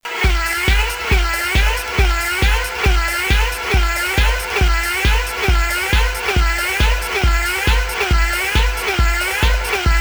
Comme le dis le mec dans les commentaires.. une petite ligne de bass par dessus.. y'a un putain de son techno :D
hop! fait en 2 minutes avec Soundtracker sur Amiga :
RemixFast.mp3